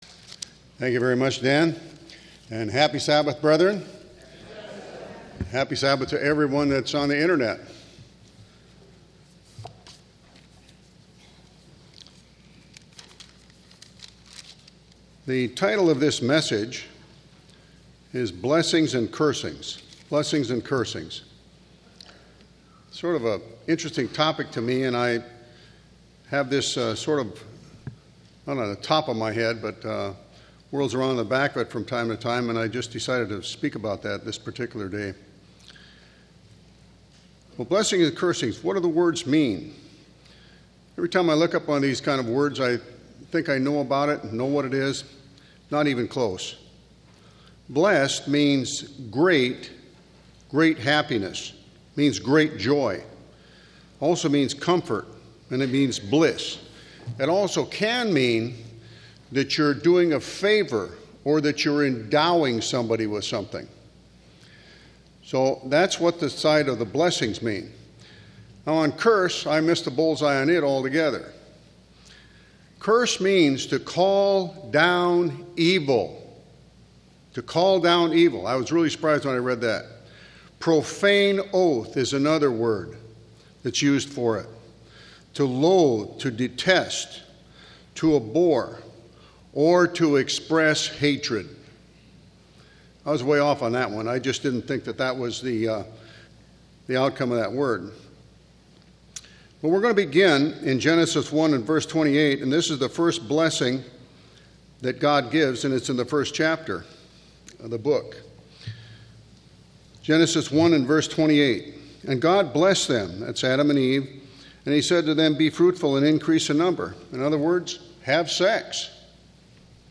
Given in Orange County, CA
UCG Sermon Blessings Studying the bible?